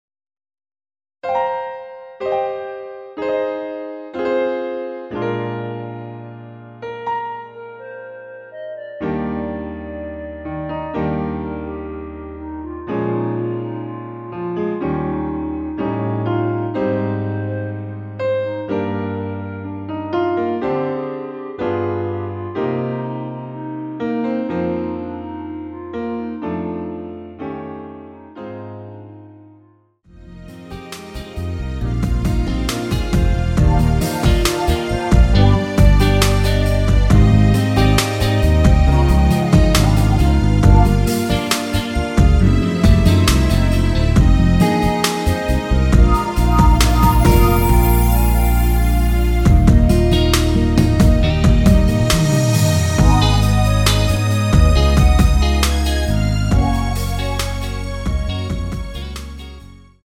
원키에서(-2)내린 멜로디 포함된 MR입니다.
Eb
앞부분30초, 뒷부분30초씩 편집해서 올려 드리고 있습니다.
중간에 음이 끈어지고 다시 나오는 이유는